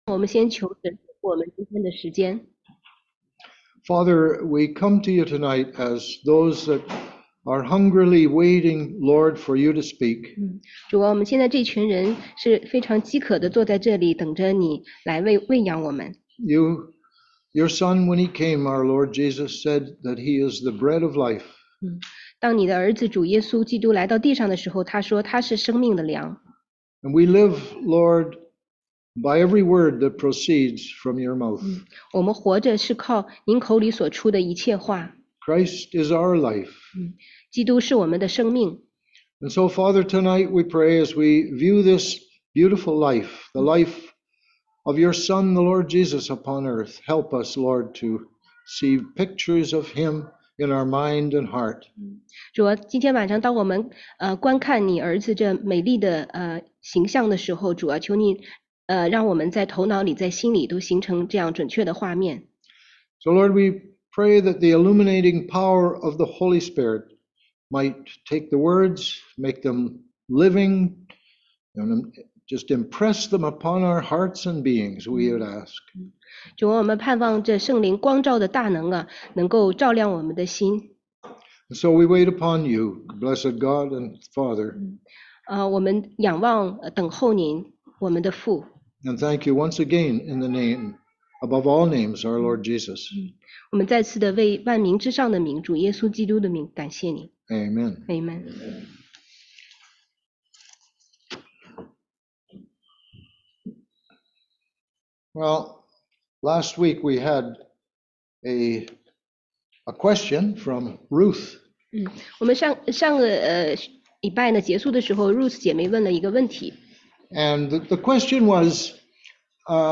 16街讲道录音 - 基督论概述（2）— 基督在旧约的出现和道成肉身
中英文查经
初级门徒培训第二课（2）.mp3